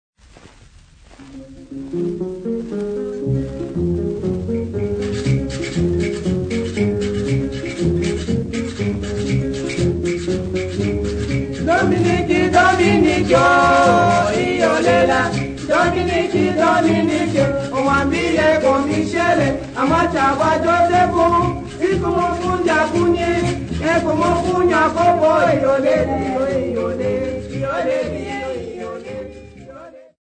Popular music--Africa
Dance music
Dance music--Caribbean Area
Lively dance song accompanied by various instruments